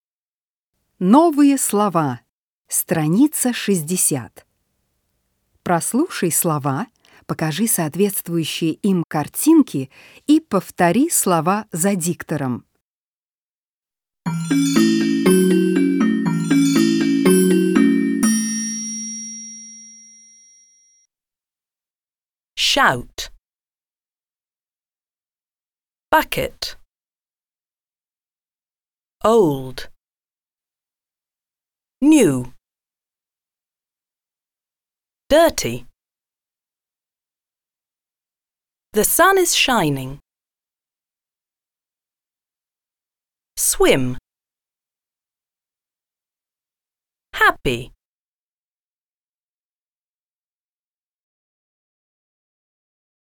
Прослушай слова, покажи соответствующие им картинки и повтори слова за диктором.
08-Новые-слова-с.-60-.mp3